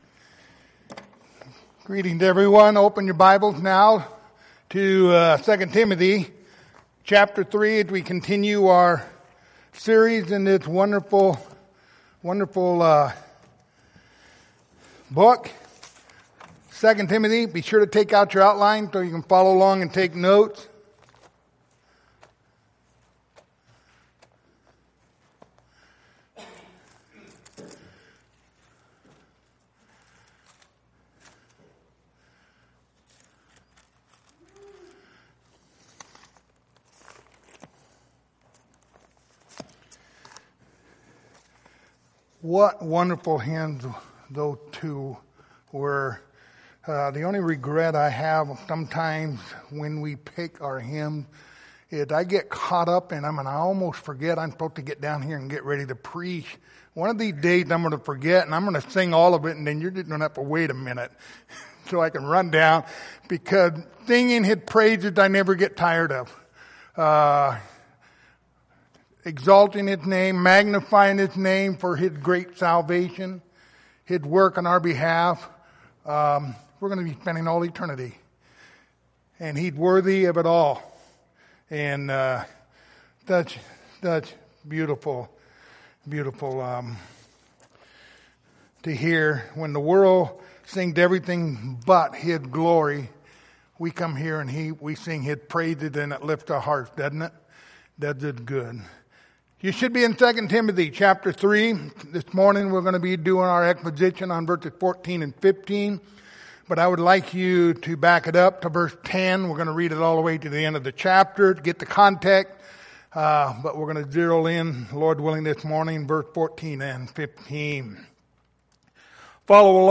Passage: 2 Timothy 3:14-15 Service Type: Sunday Morning